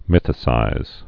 (mĭthĭ-sīz)